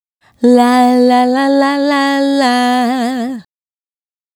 La La La 110-B.wav